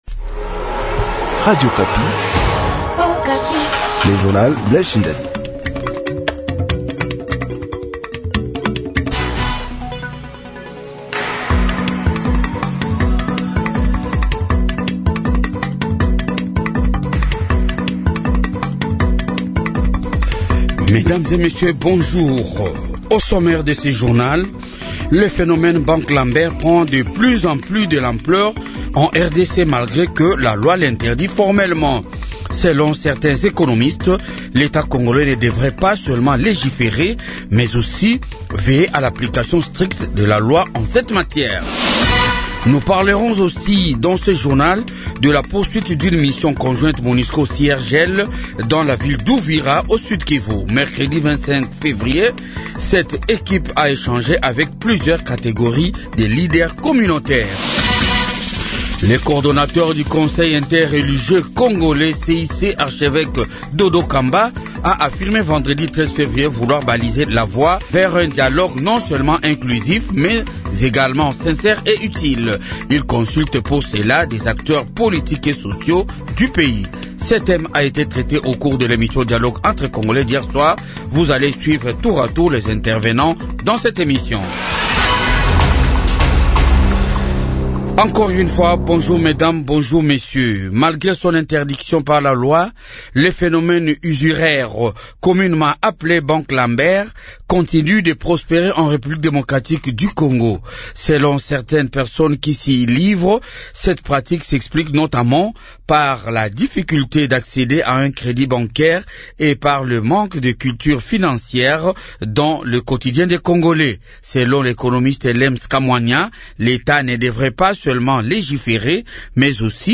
Journal du matin